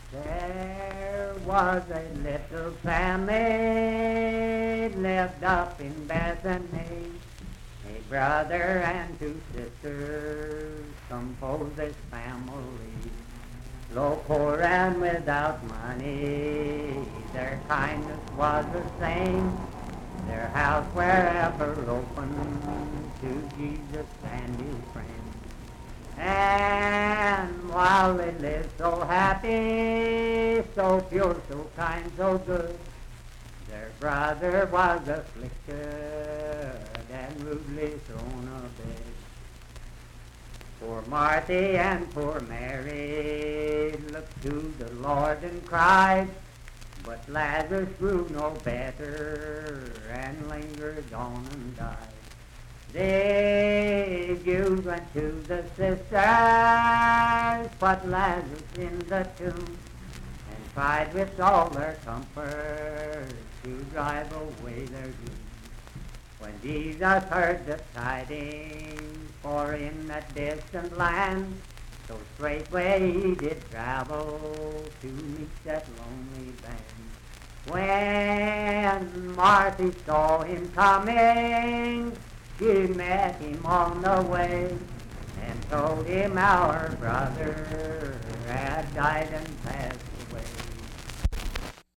Unaccompanied vocal music
Hymns and Spiritual Music
Voice (sung)
Parkersburg (W. Va.), Wood County (W. Va.)